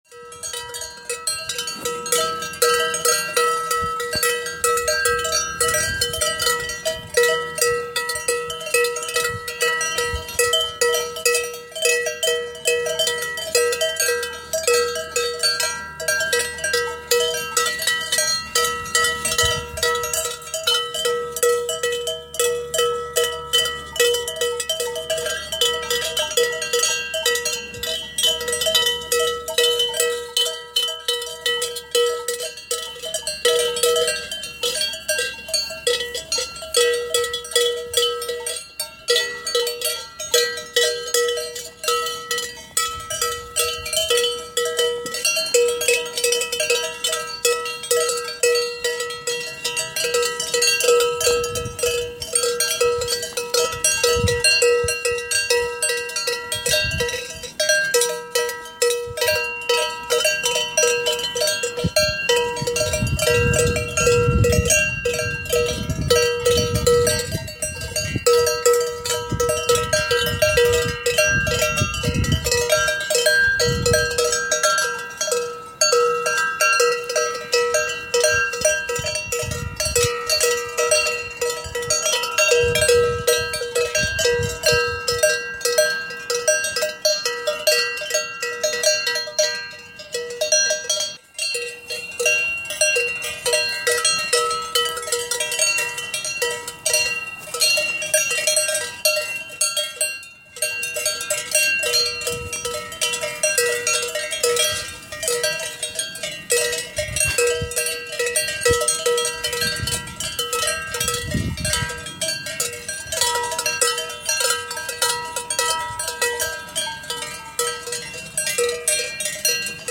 A chorus of cow bells, Monte Grappa
Cows feed on the slopes of Monte Grappa - a chorus of cow bells.